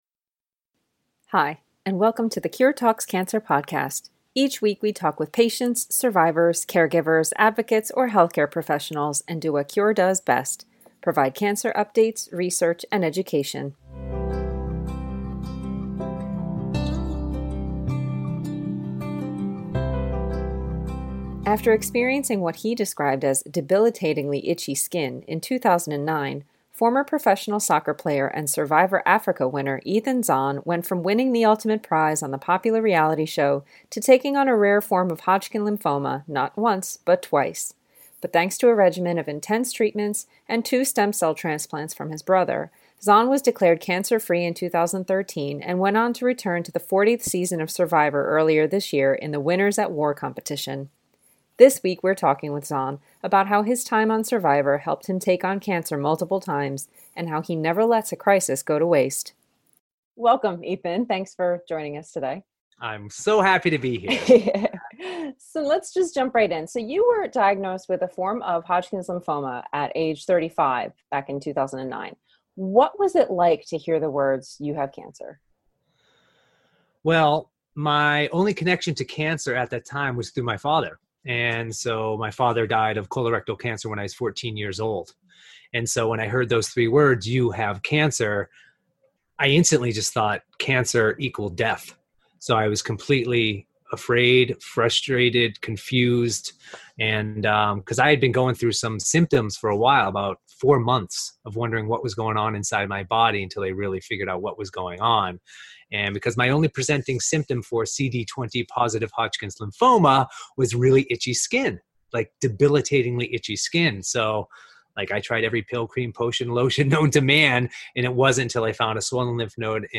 But thanks to a regimen of intense treatments and two stem cell transplants from his brother, Zohn was declared cancer-free in 2013 and went on to return to the 40th season of “Survivor” earlier this year in the Winners At War competition. This week, we’re talking with Zohn about how his time on “Survivor” helped him take on cancer multiple times – and about how he never lets a crisis go to waste.